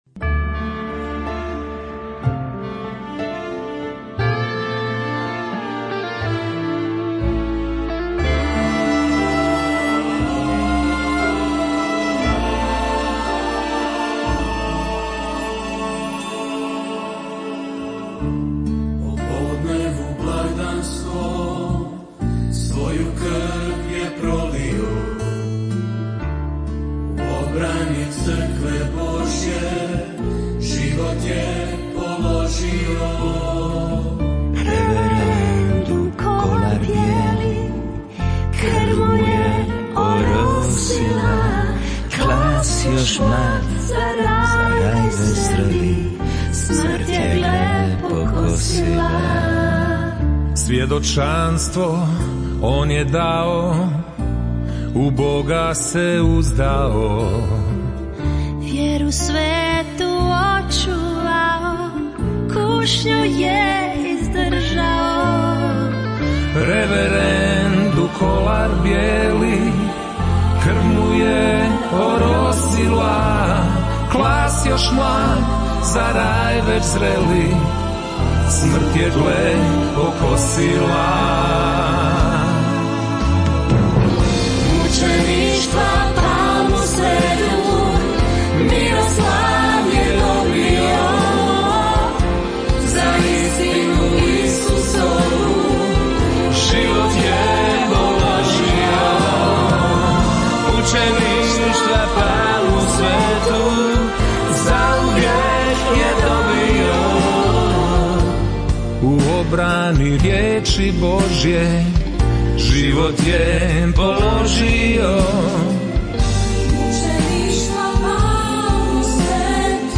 Prigodna emisija uz ovogodišnji Holywin